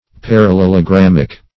Parallelogrammic \Par`al*lel`o*gram"mic\ (p[a^]r`al*l[e^]l`[-o]*gr[a^]m"m[i^]k)